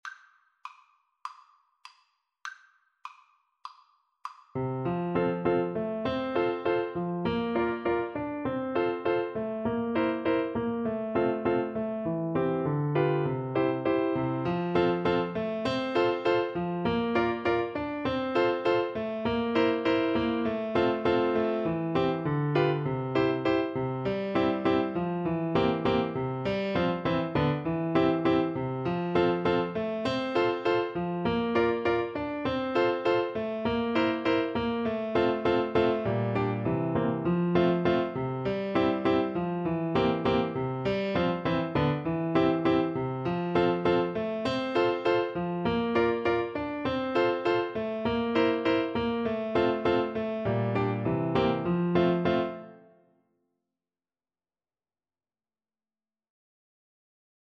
4/4 (View more 4/4 Music)
~ = 100 Frisch und munter